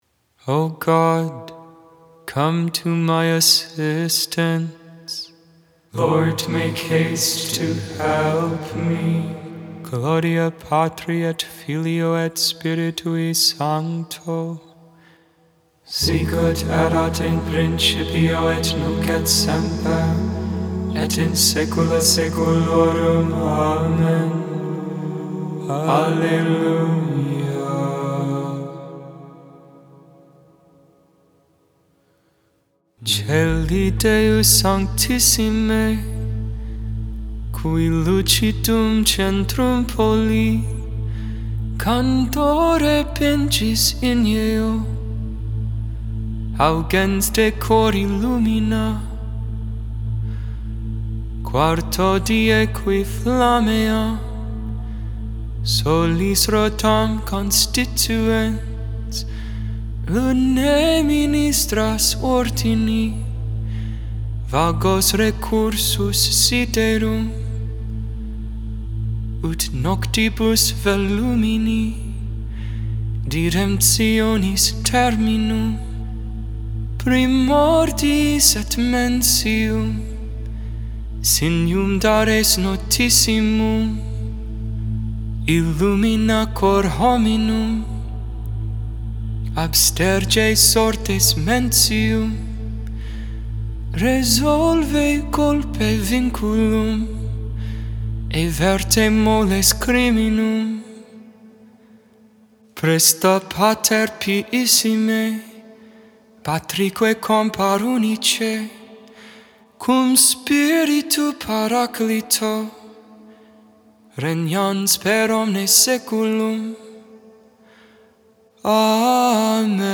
Gregorian tone 4E